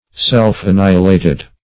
\Self`-an*ni"hi*la`ted\